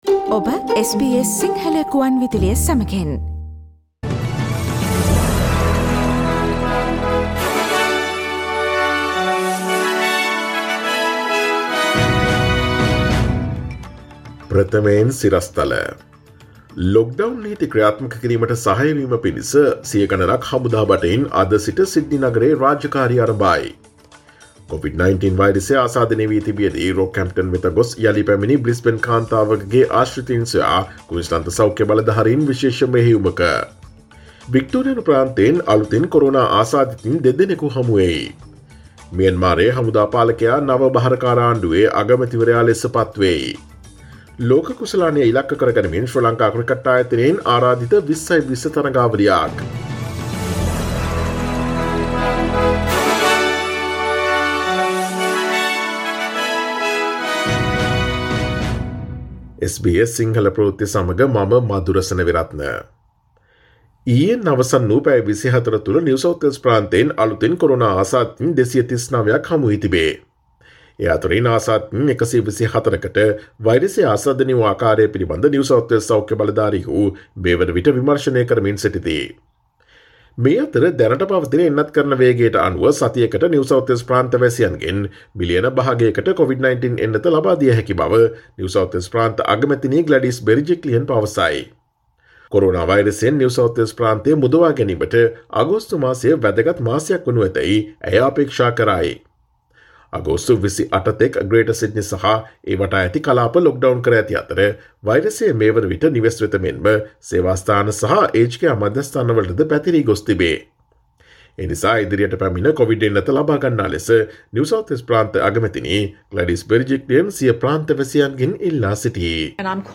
ඔස්ට්‍රේලියාවේ, ජාත්‍යන්තරයේ සහ ක්‍රීඩා ක්ෂේත්‍රයේ නවතම පුවත් රැගත් SBS සිංහල සේවයේ 2021 අගෝස්තු 2 වනදා සඳුදා වැඩසටහනේ ප්‍රවෘත්ති ප්‍රකාශයට සවන්දෙන්න.